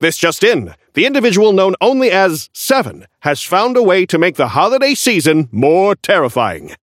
Newscaster voice line - This just in: The individual known only as "Seven" has found a way to make the holiday season more terrifying.
Newscaster_seasonal_gigawatt_unlock_01_alt_01.mp3